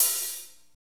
HAT F S L0DR.wav